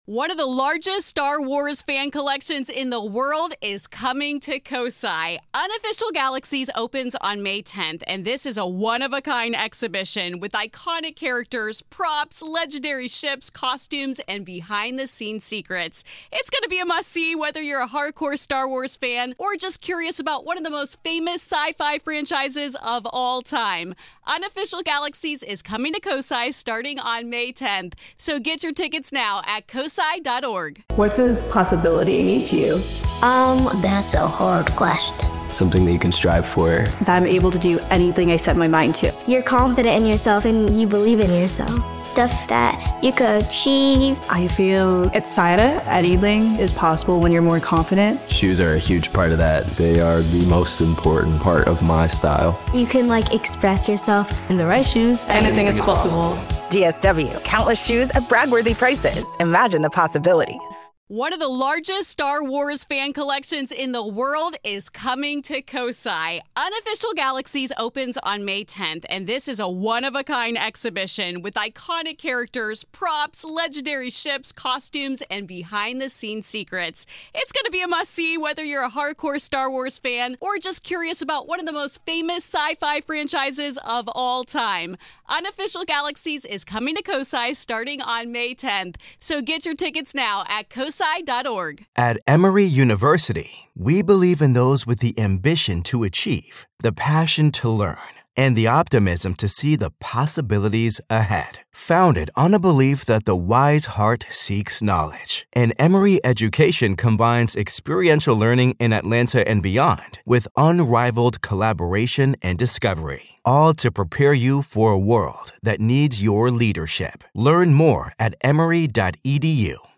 Join us as we explore his journey, the questions that arose, and the lessons learned from an experience no one could have predicted. This is Part Two of our conversation.